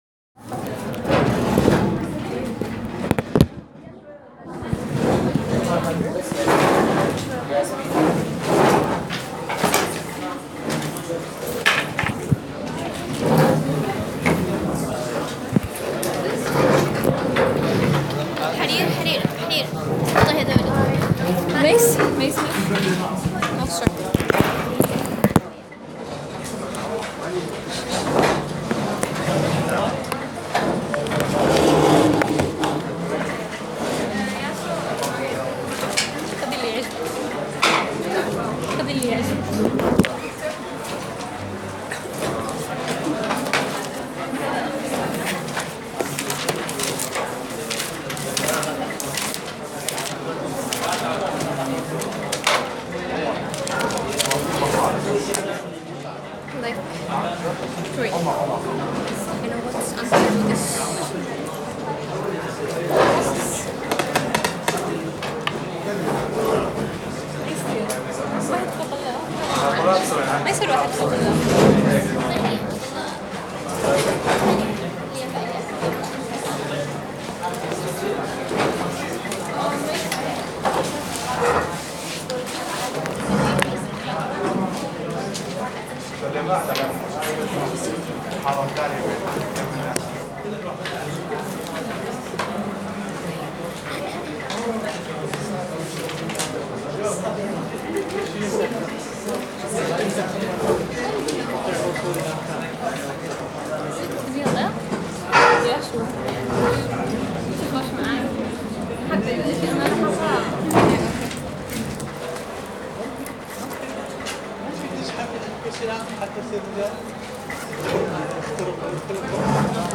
الفسلجة > محاضرة رقم 3 بتاريخ 2015-10-13